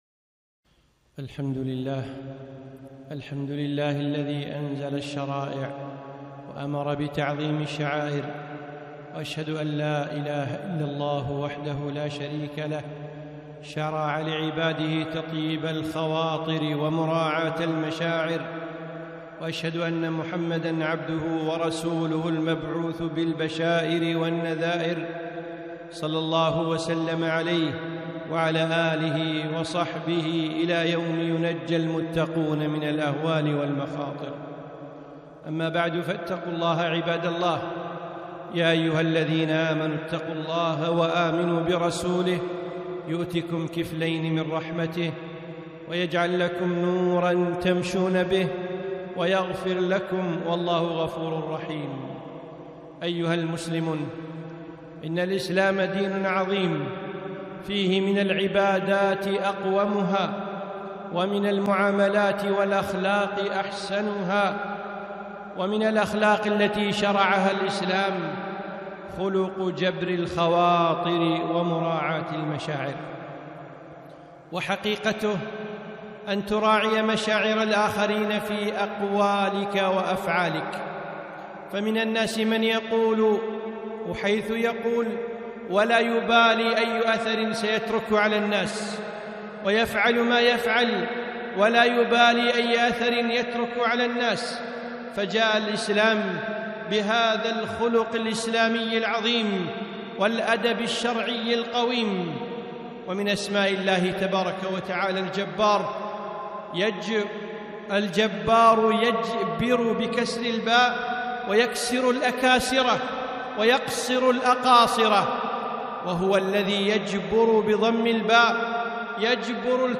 خطبة - جبر الخواطر